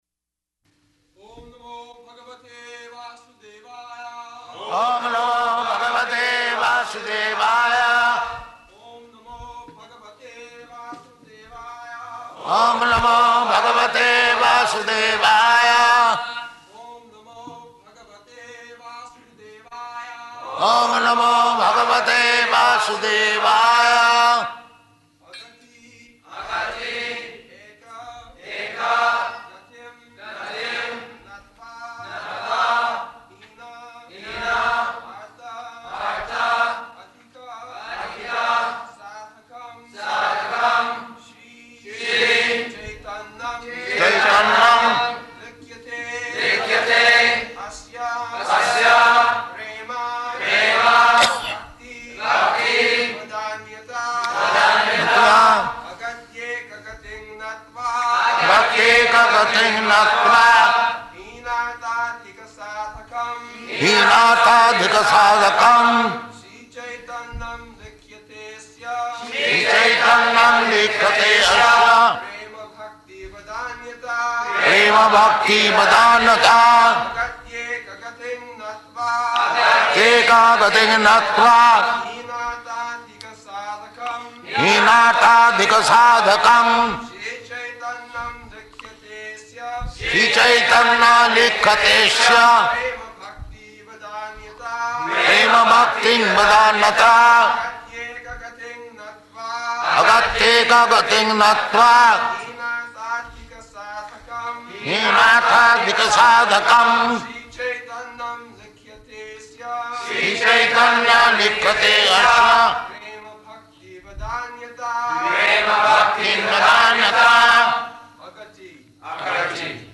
March 1st 1974 Location: Māyāpur Audio file